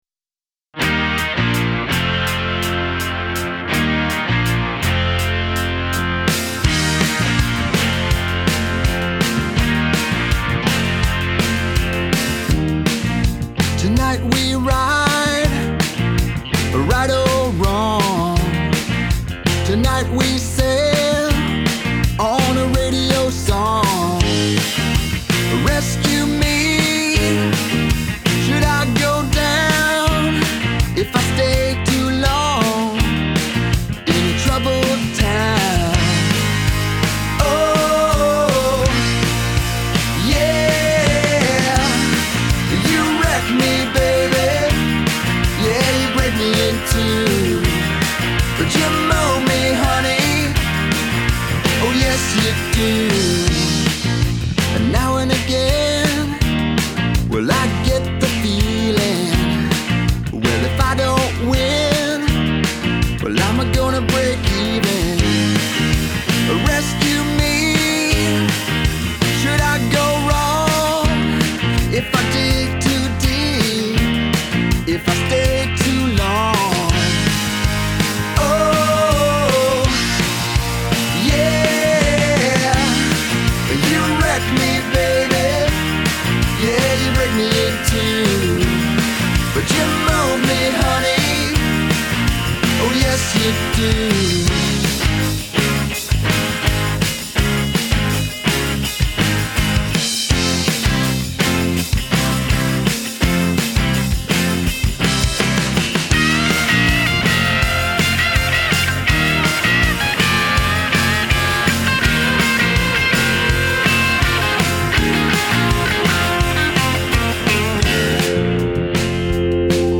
Auf den Vocals ist jeweils ein in etwa identisch eingestellter LA-3A.